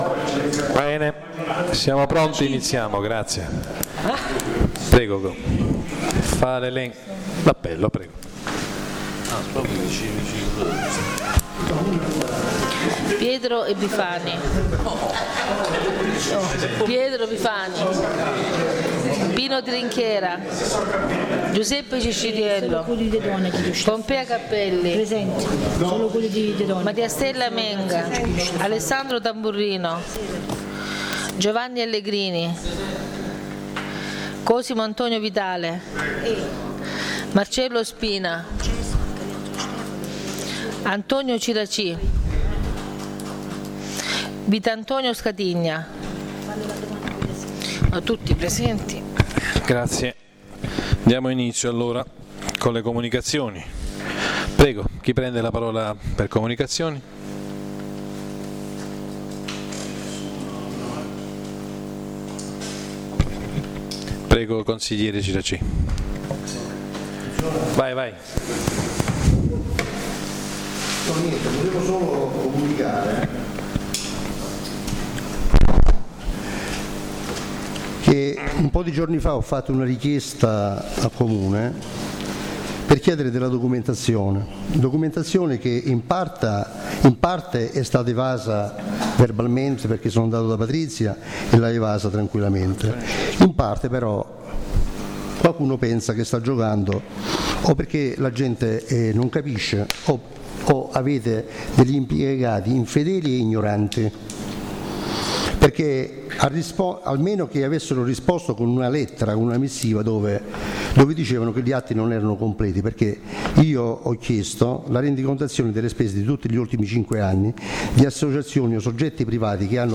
La registrazione audio del Consiglio Comunale di San Michele Salentino del 30/11/2015